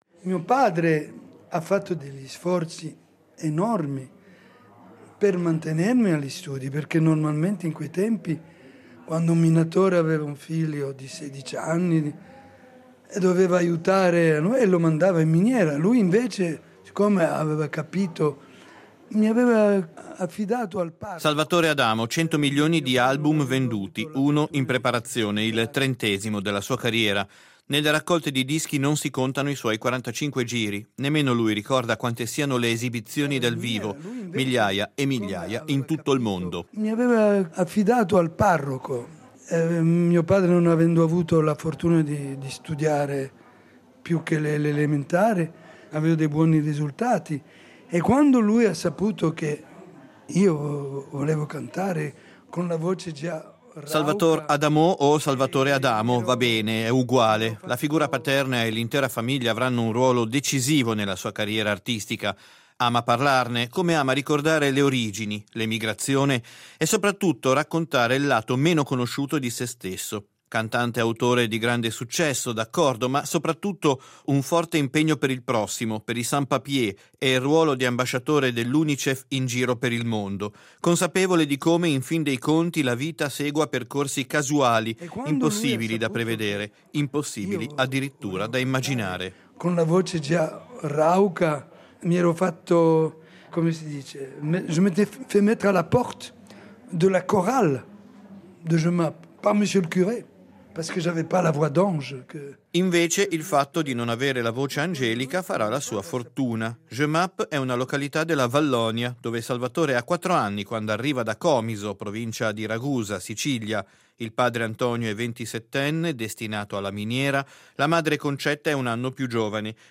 “Laser” propone anche un piccolo estratto di un inedito, eseguito solo alla rassegna della canzone d’autore “Tenco” a Sanremo, lo scorso ottobre, dedicato al tema della migrazione. Salvatore Adamo parla in prima persona, coinvolto nella questione fin da quando adolescente abitava nelle baracche della miniera.